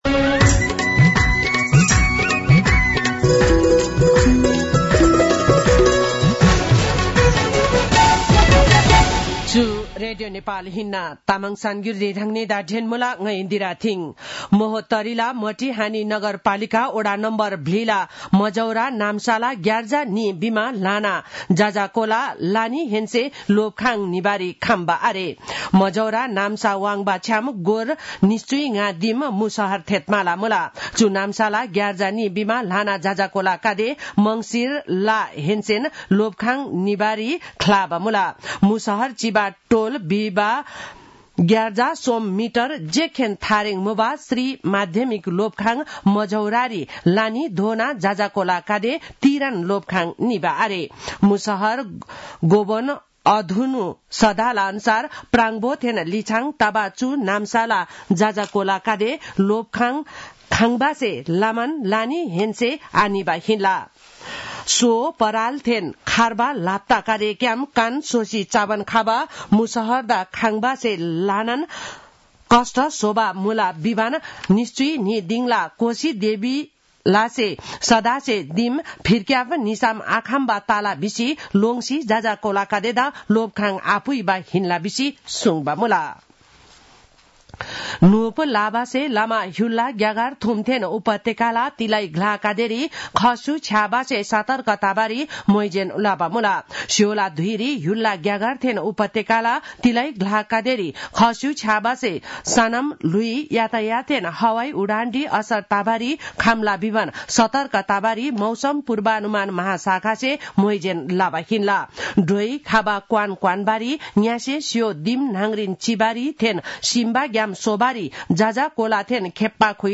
तामाङ भाषाको समाचार : ६ माघ , २०८१